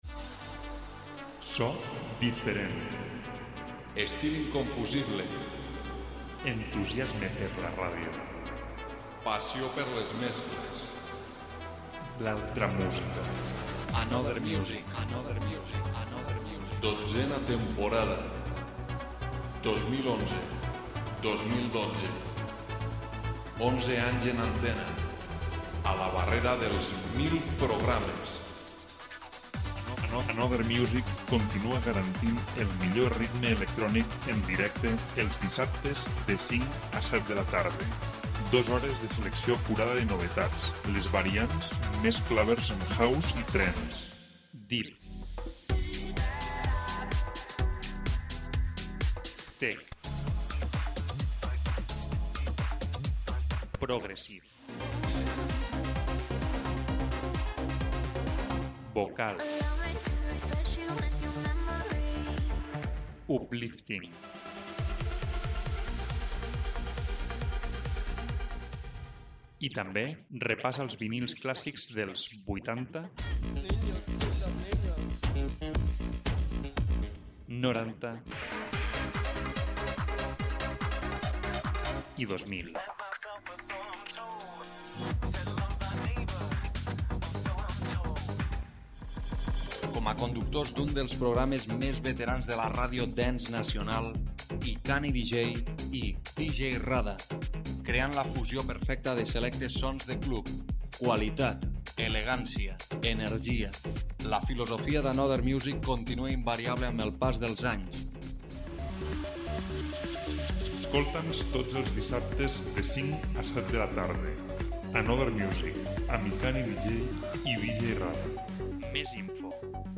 presentem novetats House i Trance d'aquest Abril 2012.